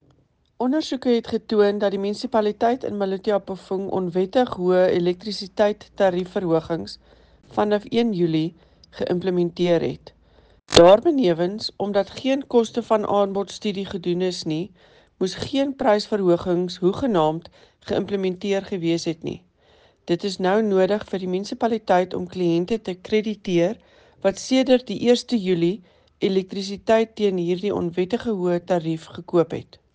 Afrikaans by Cllr Eleanor Quinta and